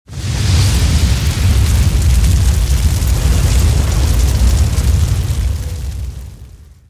soceress_skill_flamevoltex_03_explosion.mp3